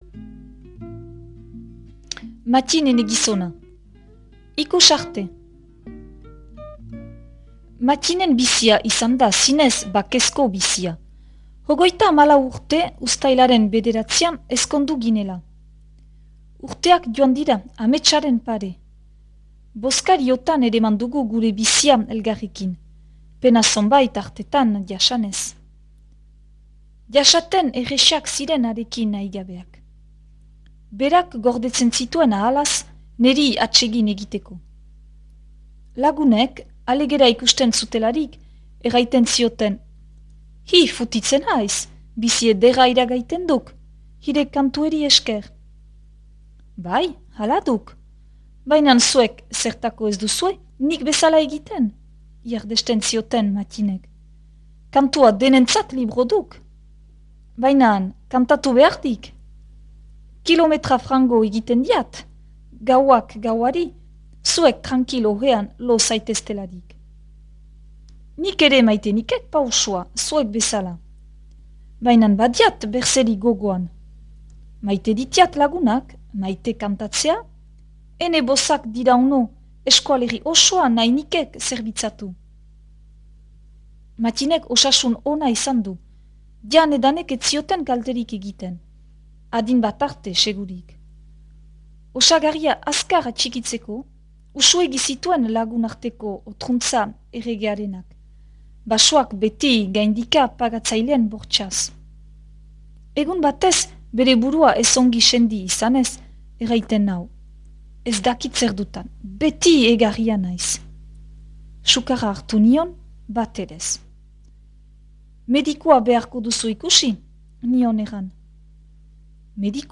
irakurketa da